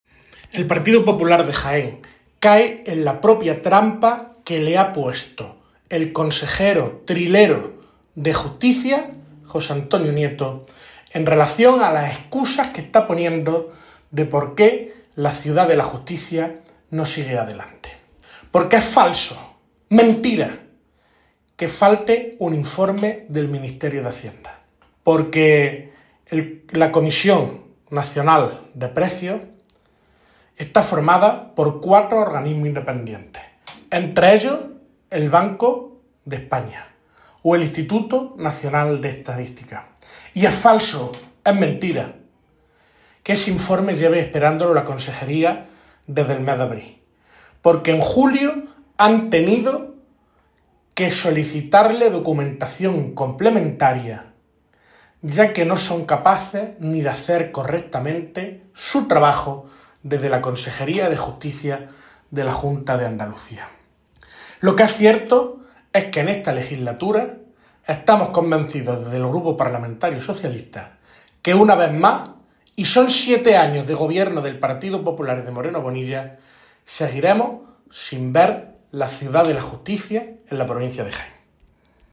Cortes de sonido # Víctor Torres